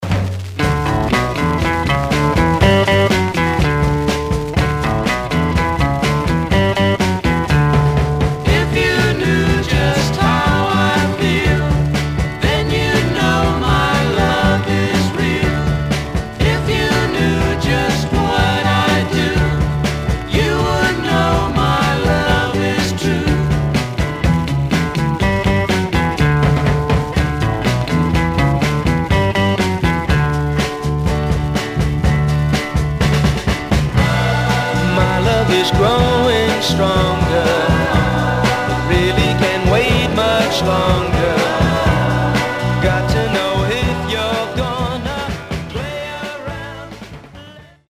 Surface noise/wear
Mono
Garage, 60's Punk Condition